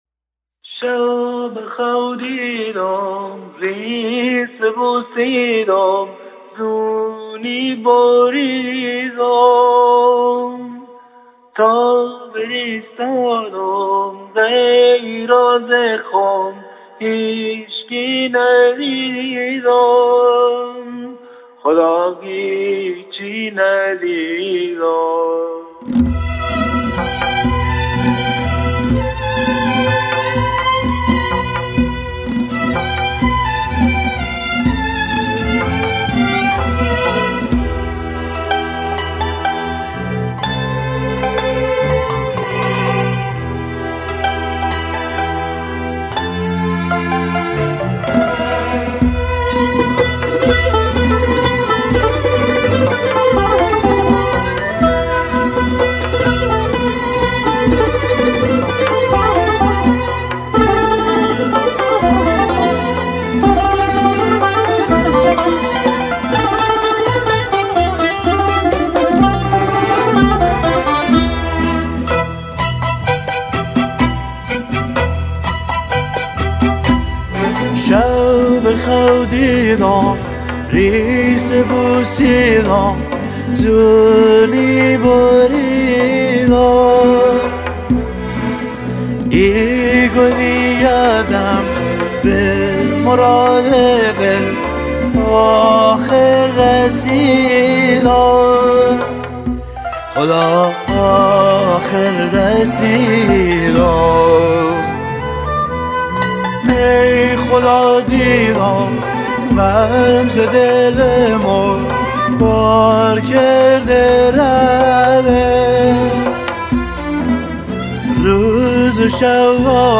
اطلاعات بیشتر:سبک: غمگین، عاشقانه، پاپ – گویش: بختیاری
کمانچه
تار
سنتور